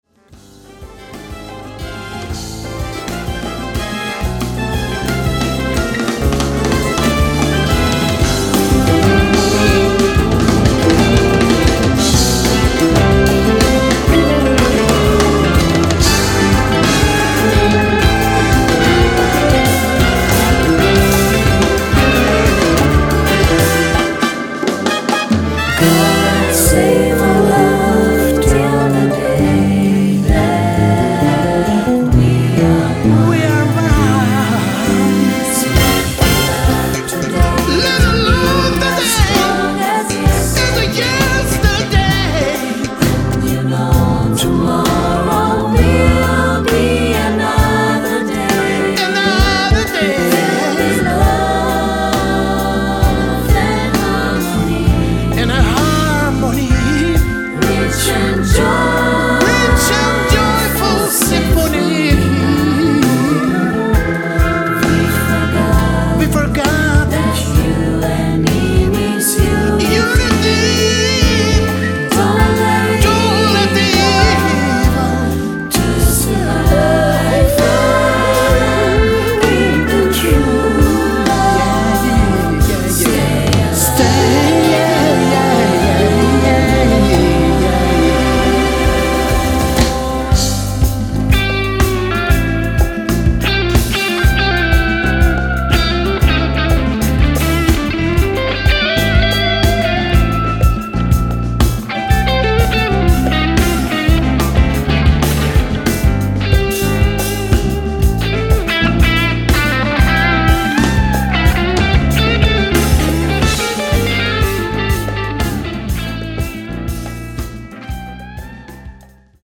keyboards, Hammond organ, piano
el. guitar, sitar
dhrupad vocal, sarangi
trombones, bass tuba